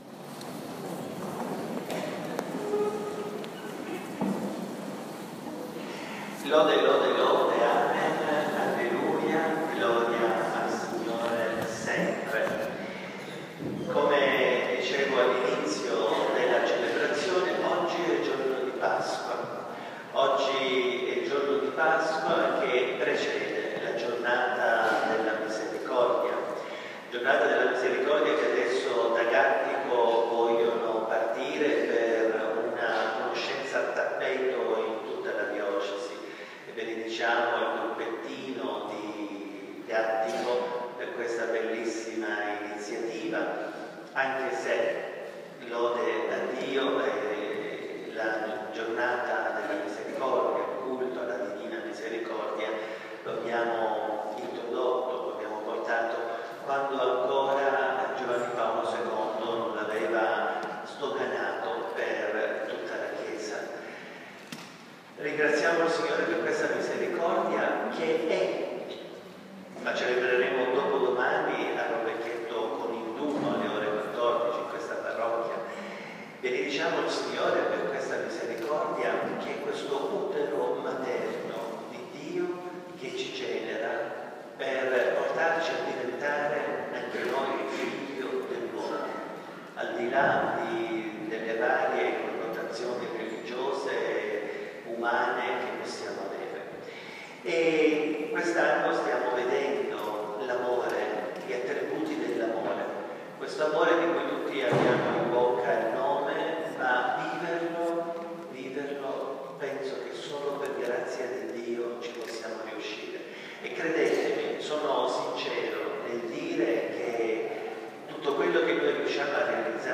L’Amore non cerca il proprio interesse - Messa di Intercessione Novara